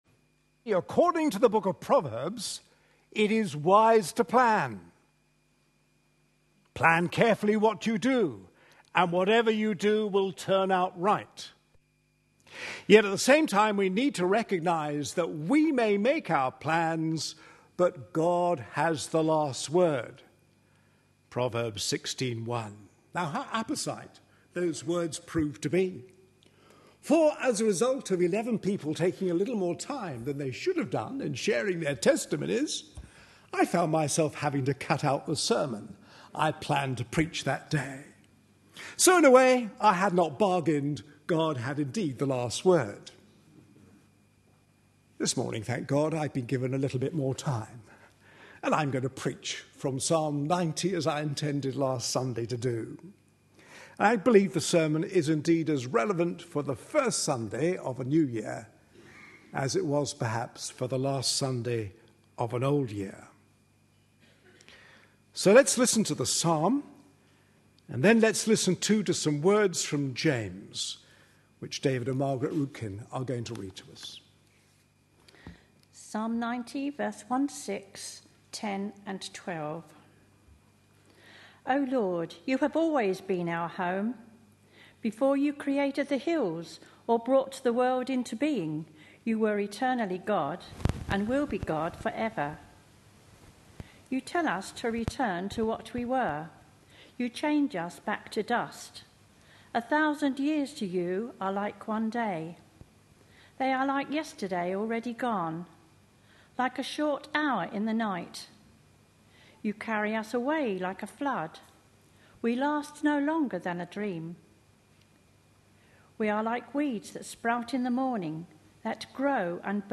A sermon preached on 6th January, 2013.